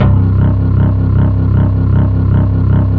B3 TONE C1.wav